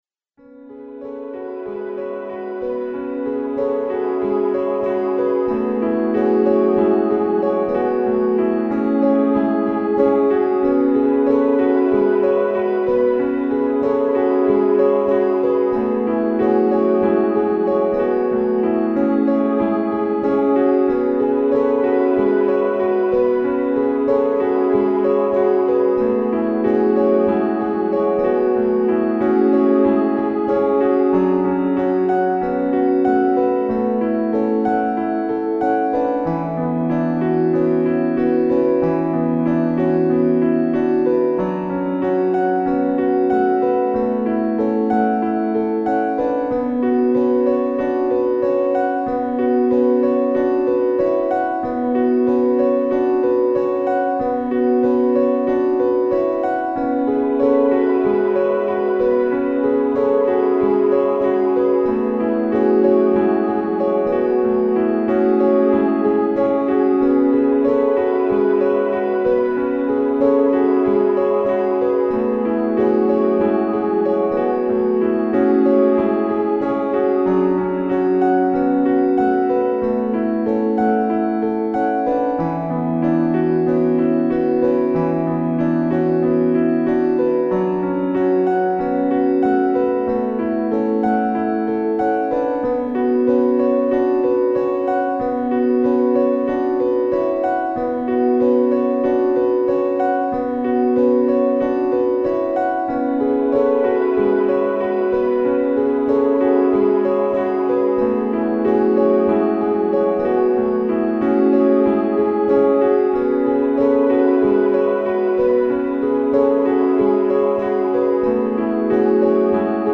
Ambient Solo Piano